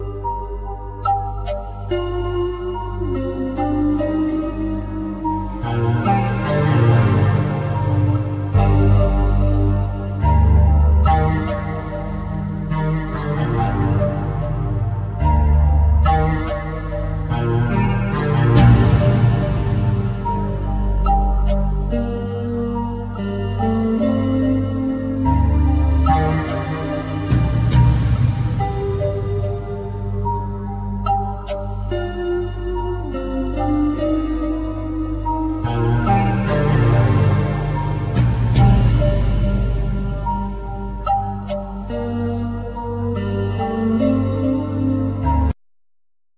Acoustic & Electric guitar,Bass
Piano,Synthsizer
Voice,Tenor Saxphone
Timpani
Small Clarinet
Tambur
Oboe